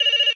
phone.ogg